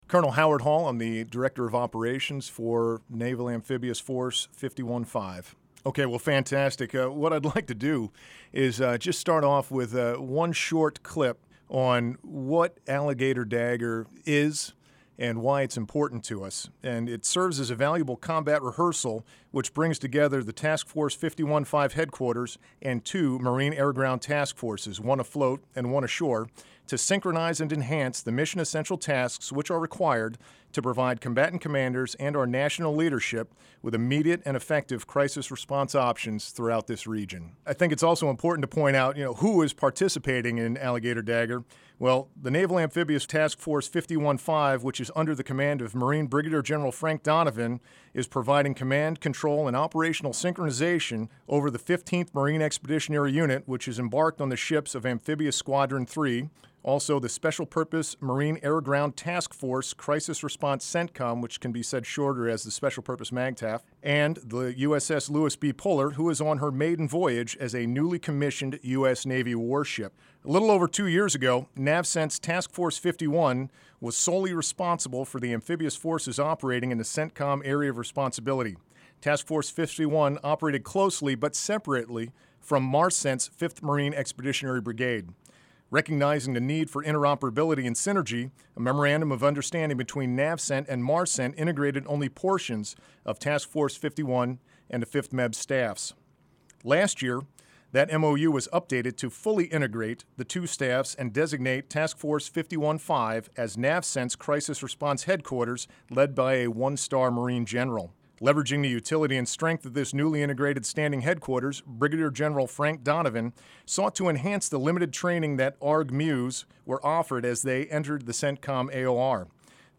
Alligator Dagger: Interview